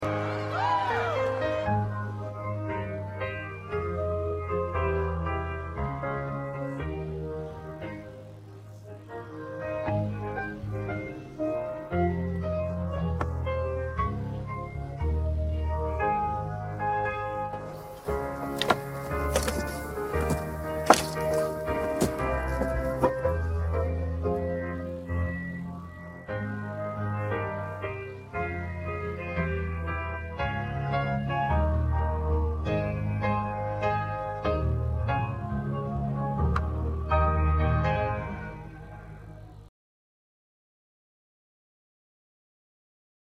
Just don’t mind the scratching sound I can’t remove it…